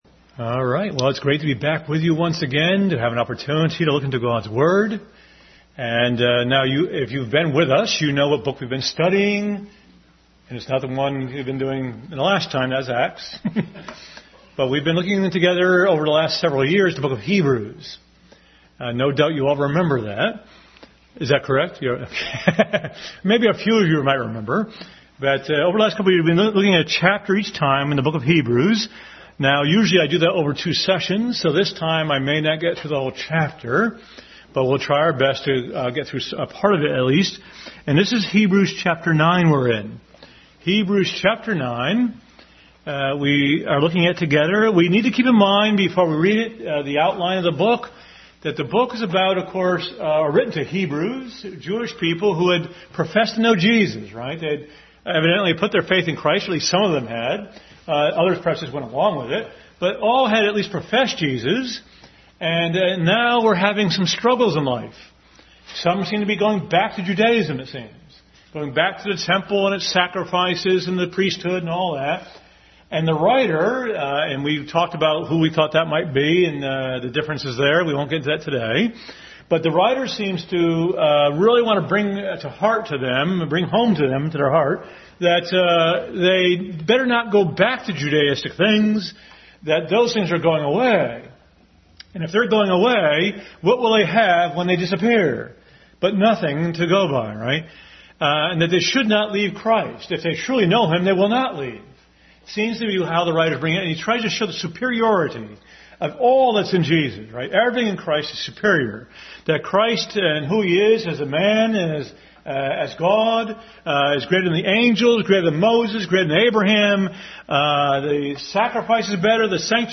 Hebrews 9:1-12 Service Type: Family Bible Hour Bible Text